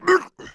Sound / sound / monster / maenghwan / damage_1.wav
damage_1.wav